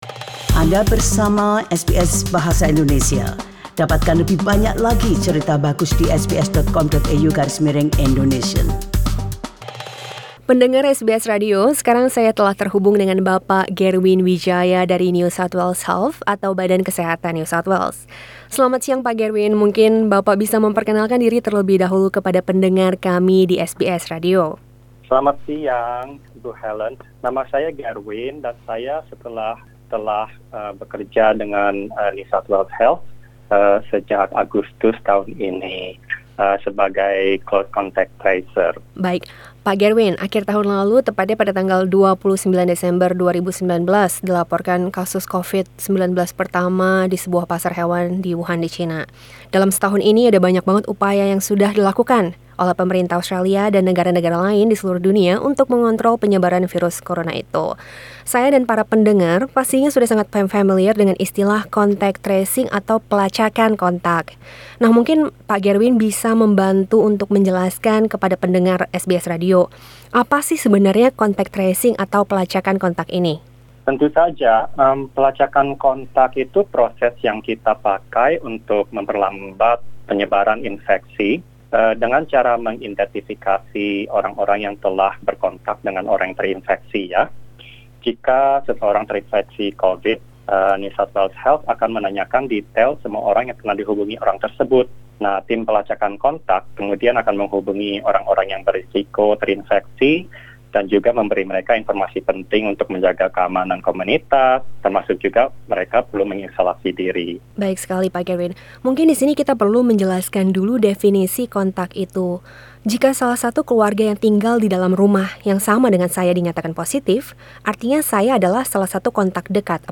Dalam wawancara berikut